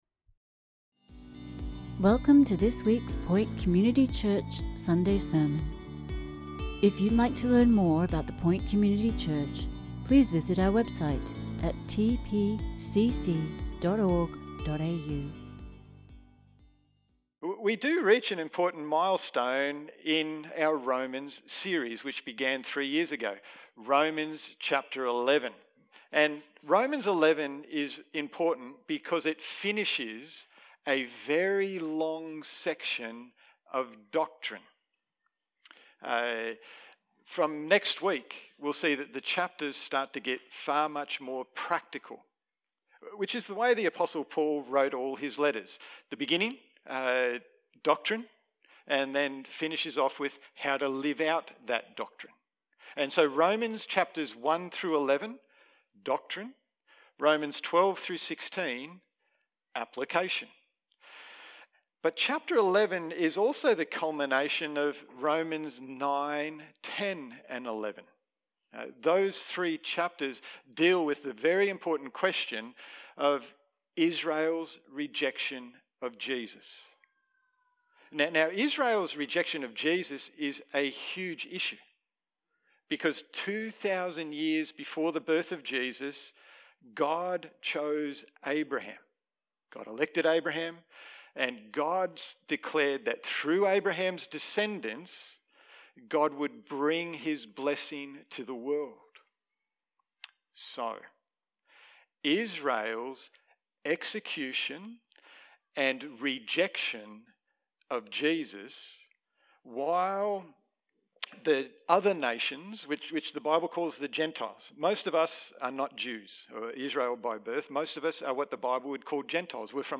Sermons | The Point Community Church